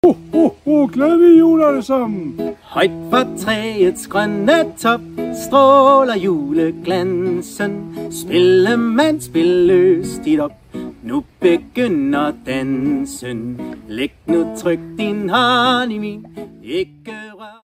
• Danske juleklassikere
Vokal
Juletrioen underholder til dit arrangement med livemusik. Rudolf og Julemanden danser med og hilser på børn og barnlige sjæle.